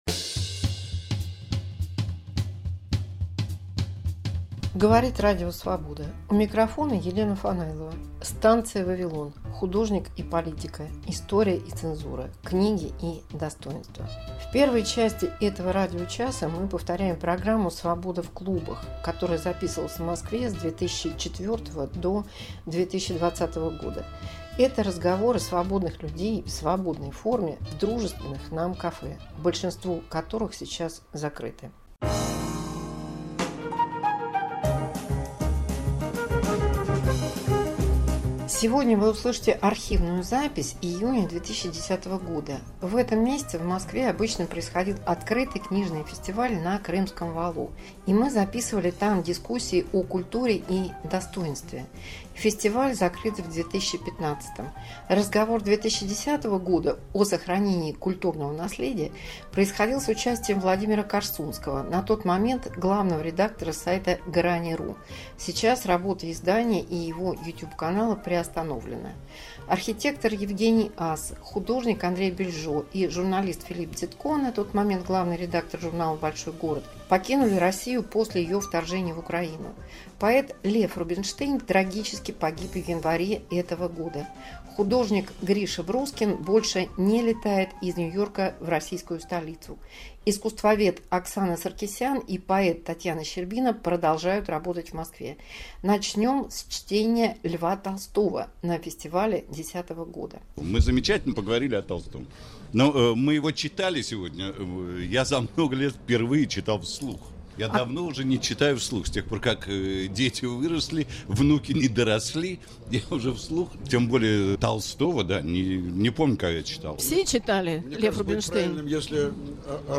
Архив 2010, разговор на Московском Открытом книжном фестивале